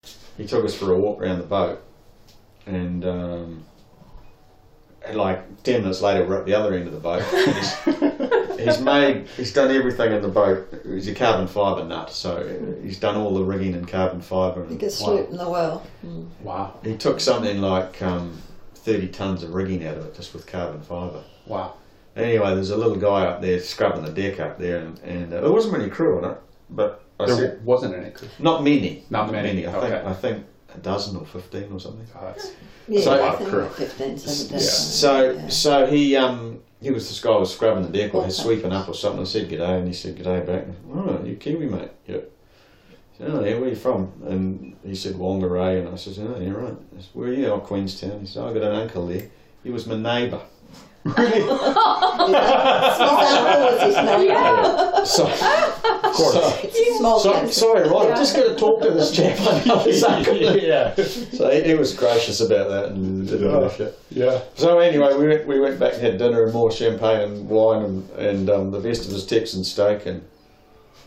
Interview with a Cruiser: Kiwi Dream – S/V Madrone
Marina Estancilla- Valdivia, Chile